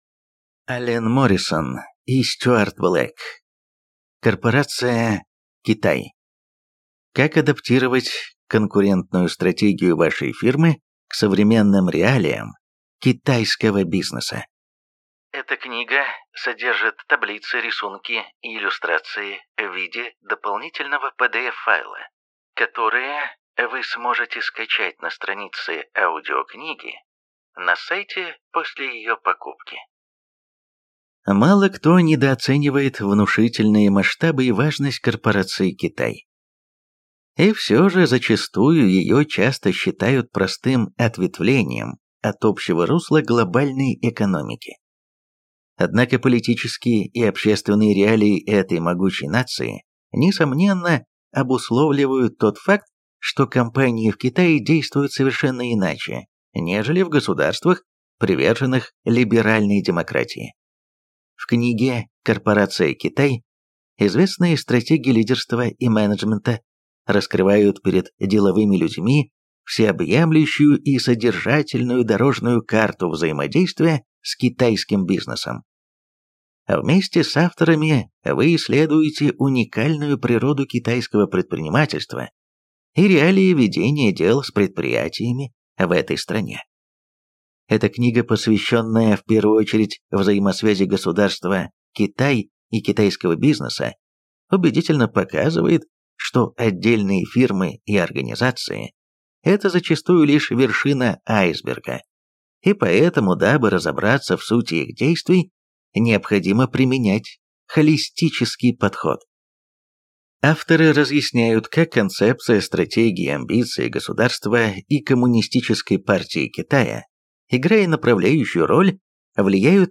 Аудиокнига Корпорация Китай. Как адаптировать конкурентную стратегию вашей фирмы к современным реалиям китайского бизнеса | Библиотека аудиокниг